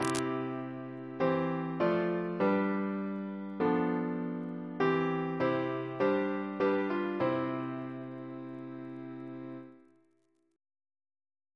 Single chant in C Composer: John Jones (1757-1833), Organist of St. Paul's Cathedral Reference psalters: OCB: 176